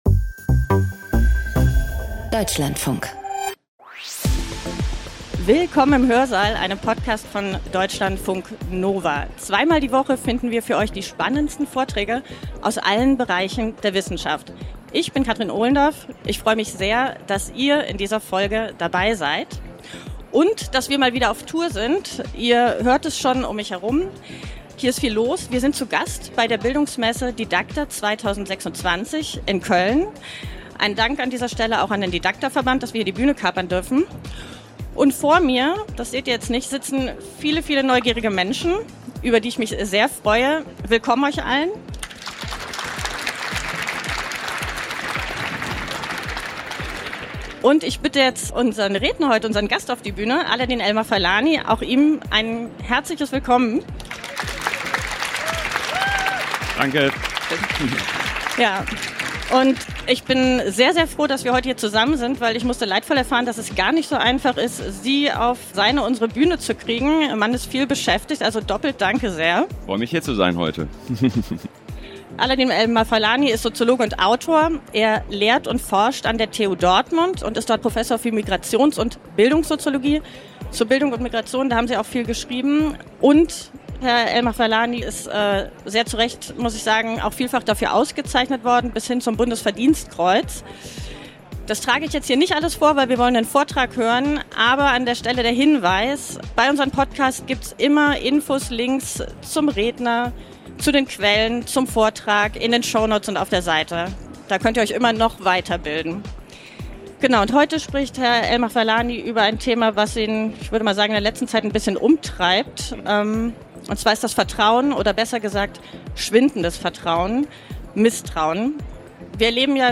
Über mögliche Gründe und Lösungen spricht er im Hörsaal Live-Podcast.